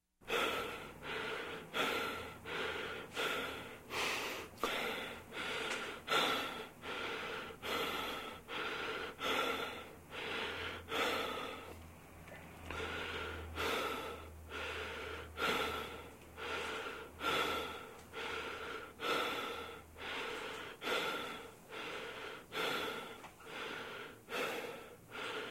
HardBreath.ogg